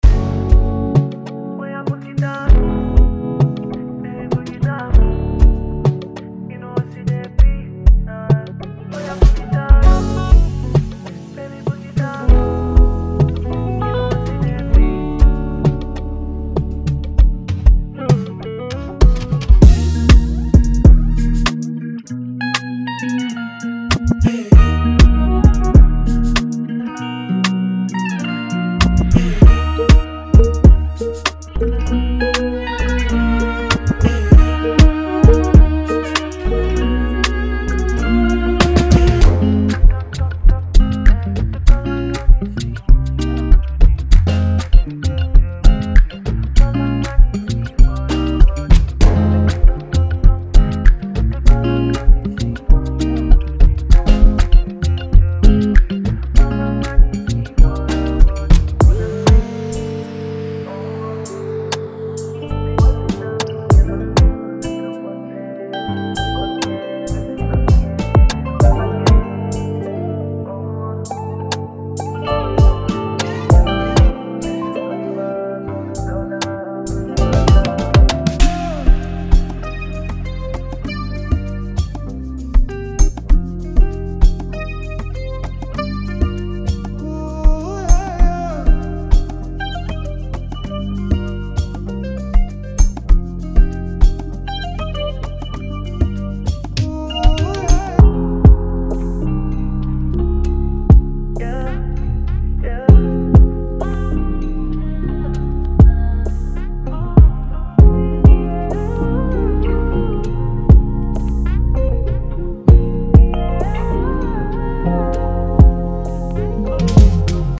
– 45+ Chord & Melody Loops
– 35+ Guitar Loops
– 110+ drum and percussion loops